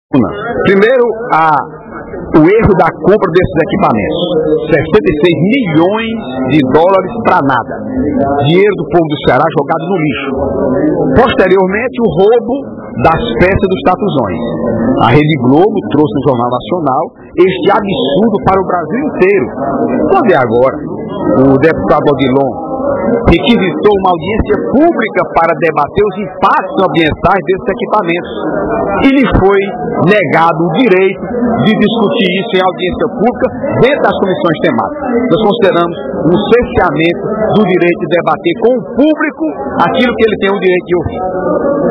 O deputado Heitor Férrer (PSB) lamentou, durante o primeiro expediente da sessão plenária desta quarta-feira (12/04), a rejeição de requerimento do deputado Odilon Aguiar (PMB) pela Assembleia.